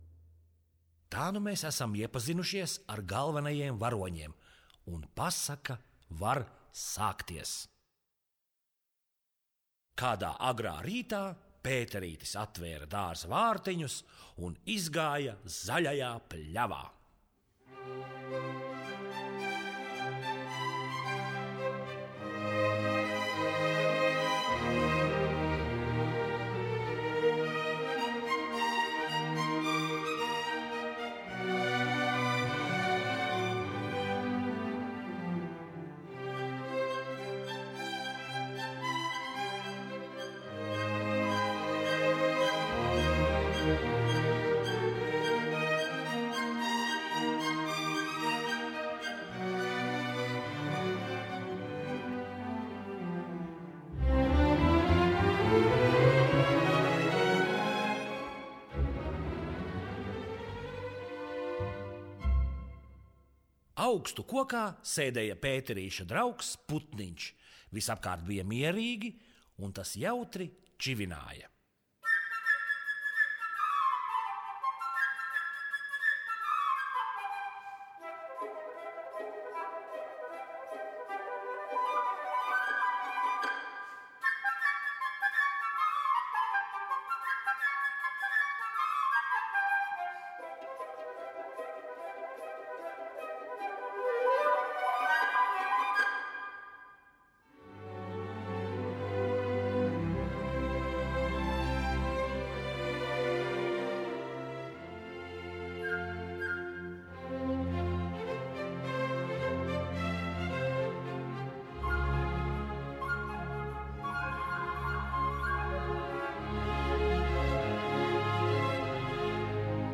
simfoniskā pasaka latviešu valodā